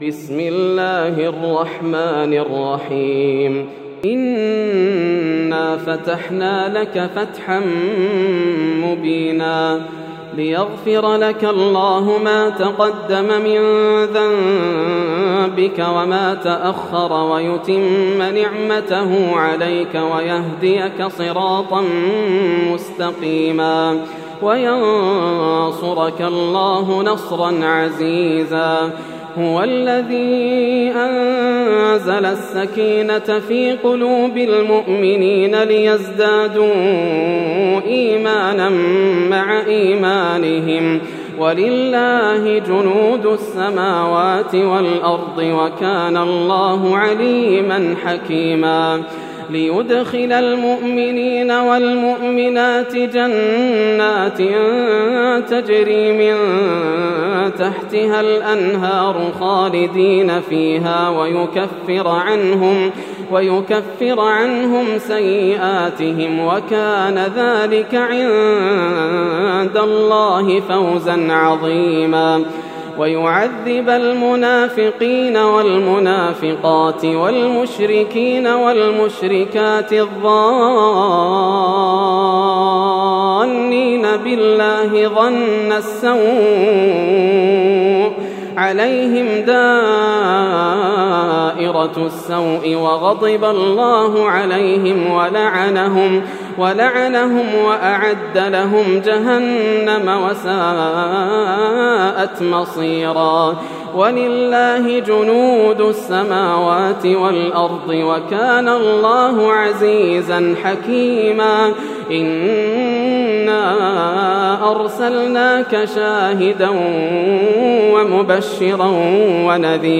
سورة الفتح > السور المكتملة > رمضان 1431هـ > التراويح - تلاوات ياسر الدوسري